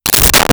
Camera Flash Pot
Camera Flash Pot.wav